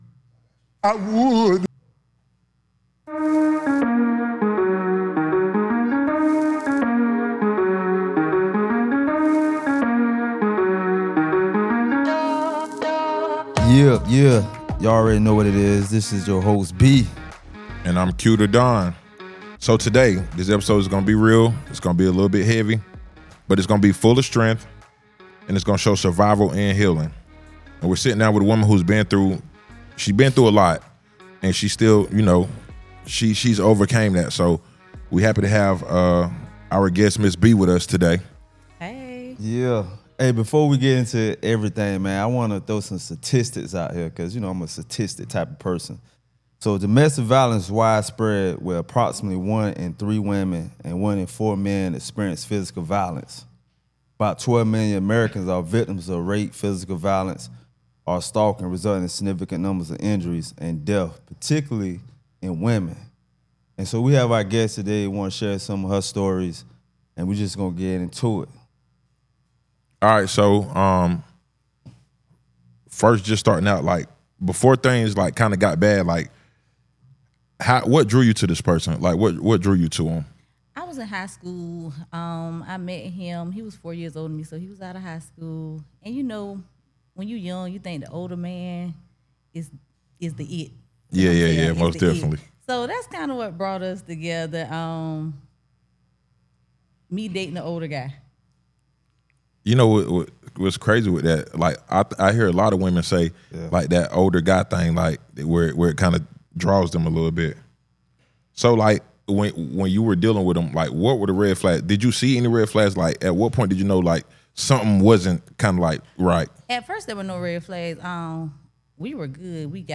In this powerful episode, we hear the raw and unfiltered story of a survivor who overcame years of domestic abuse and emerged stronger for herself and her children.